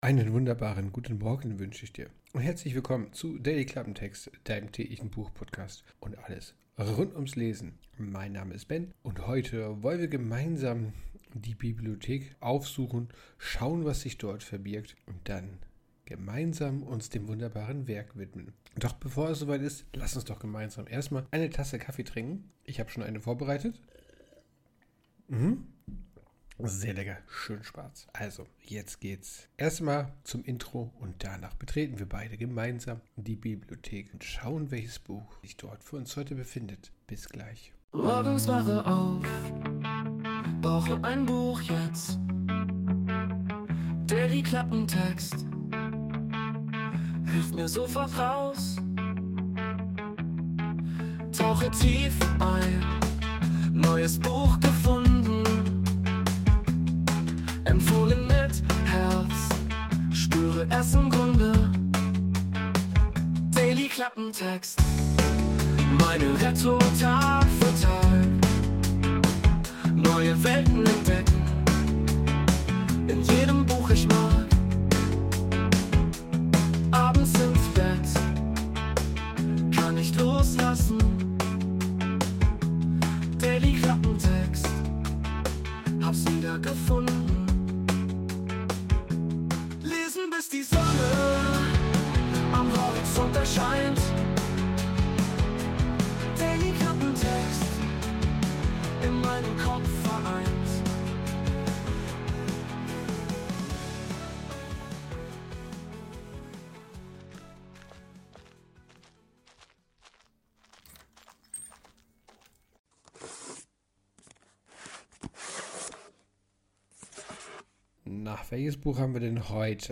Intromusik: Wurde mit der KI Suno erstellt.